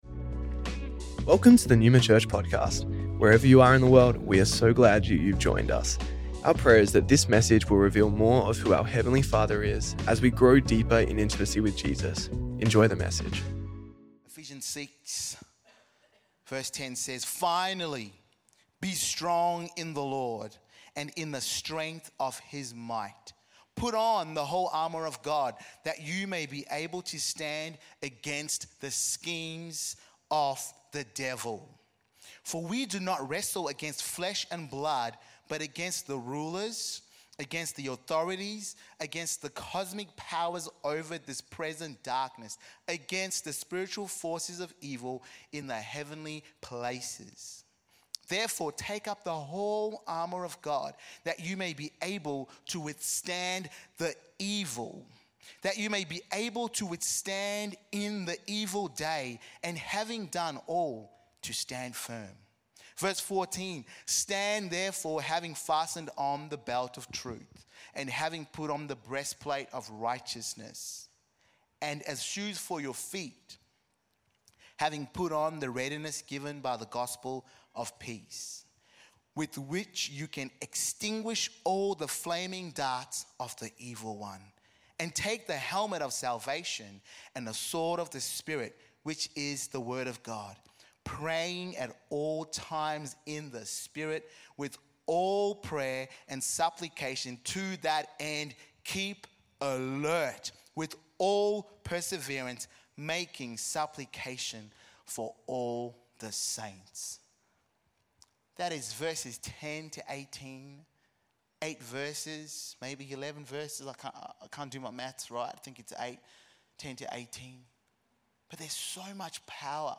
Neuma Church Melbourne South Originally Recorded at the 10AM service on Sunday 26th January 2025&nbsp